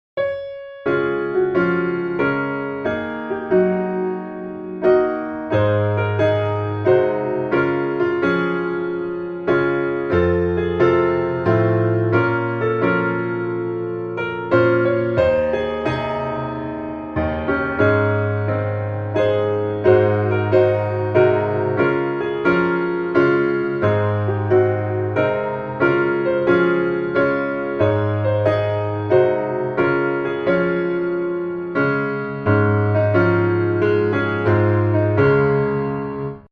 Db Major